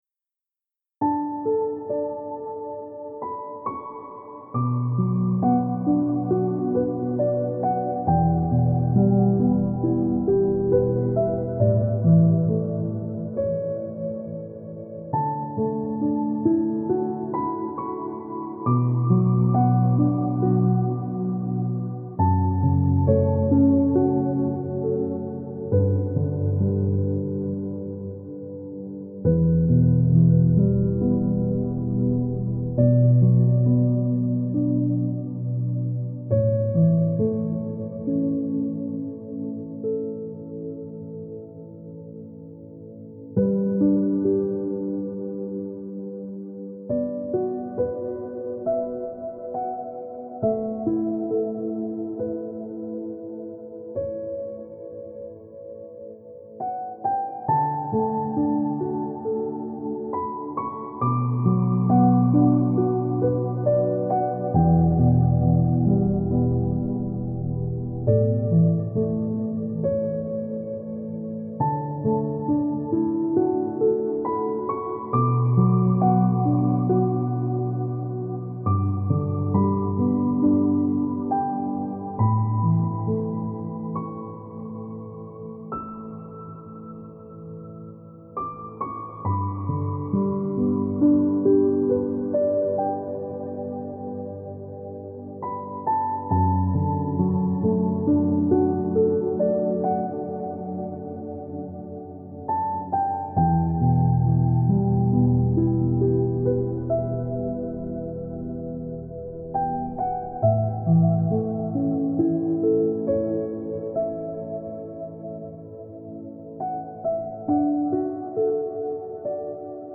это вдохновляющая композиция в жанре неоклассической музыки